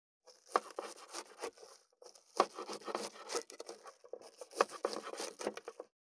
538野菜切る,咀嚼音,ナイフ,調理音,まな板の上,料理,
効果音厨房/台所/レストラン/kitchen食器食材